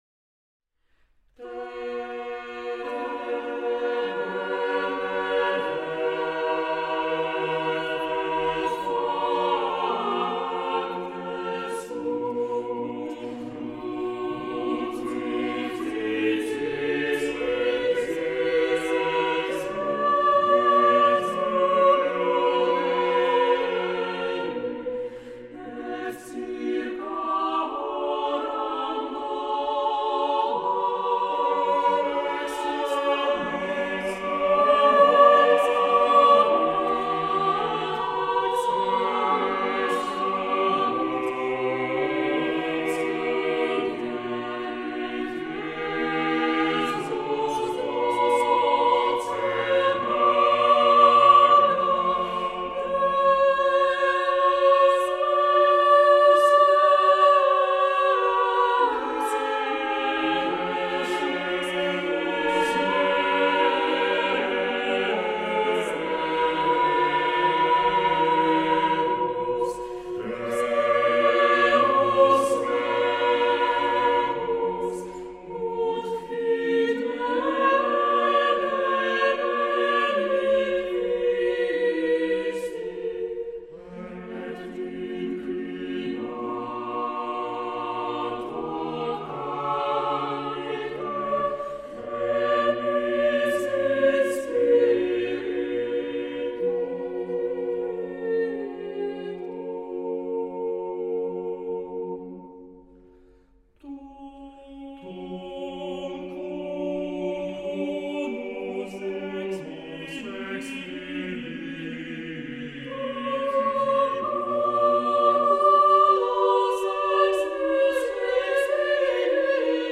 Posłuchaj rozmowy i muzyki w wykonaniu chóru Cantores Minores Wratislavienses: